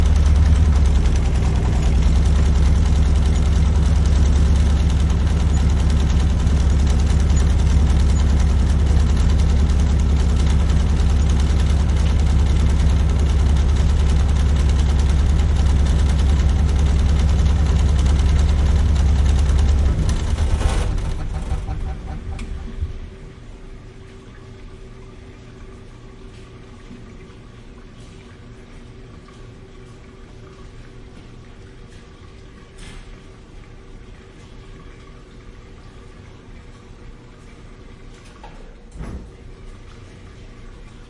自助洗衣店 " 自助洗衣店的洗衣机 震动的声音2
描述：洗衣店洗衣机洗衣机拨浪鼓vibrate2.flac
Tag: 洗衣店 垫圈 振动 拨浪鼓 洗涤